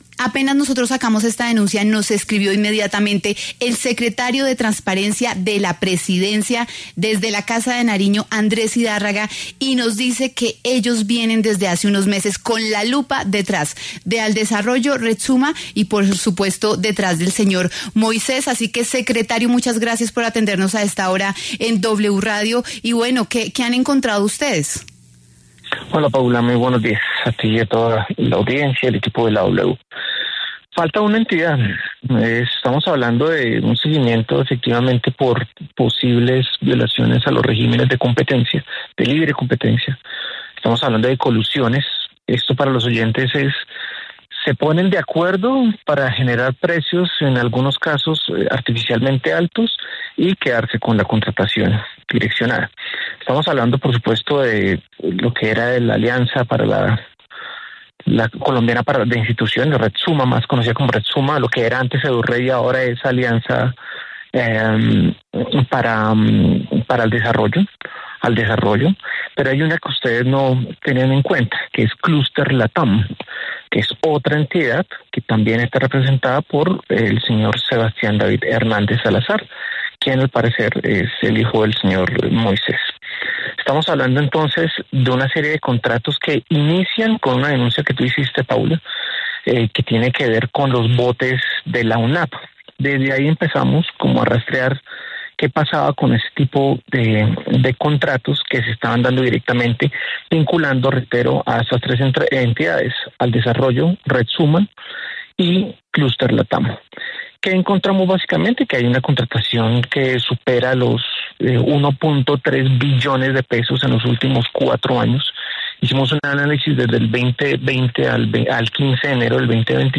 Andrés Idárraga, secretario de Transparencia, explicó en La W las investigaciones que se llevan a cabo alrededor de Red Summa, el ‘contratadero’ denunciado por este medio.